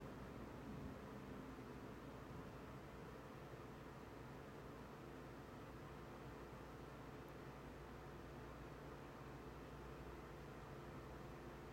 All clips were recorded under the same conditions, using an iPhone 16 Pro placed 3 feet away from the fan, with the fan running at full speed and blowing away from the microphone.